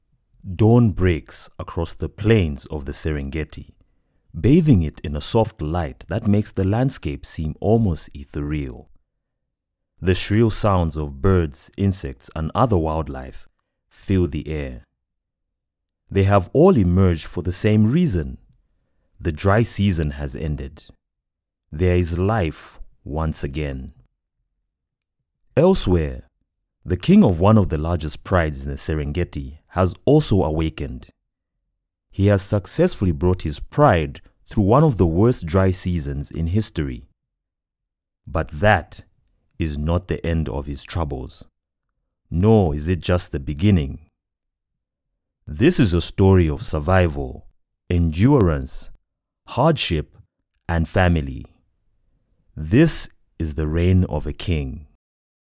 Inglés (África)
Narración
Sennheiser MKH 416
ProfundoBajo